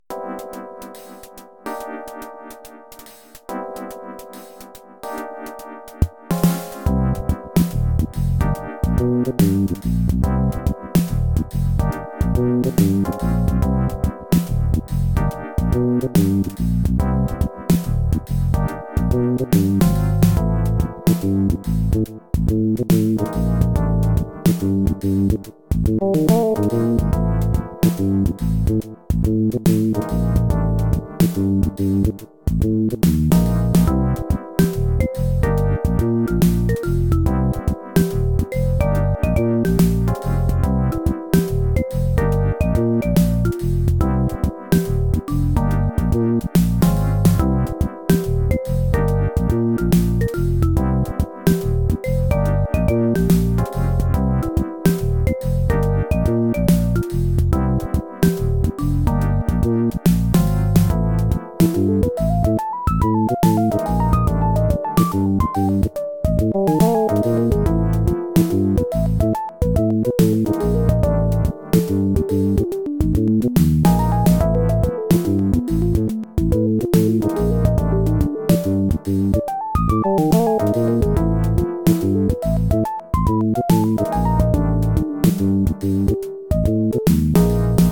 YM3812 (OPL2, FM Operator Type-L-2)
• FM: 9 channels (2-op, 4 waveforms)
OPL-series of chips are 2-op and use different algorithms.